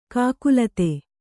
♪ kākulate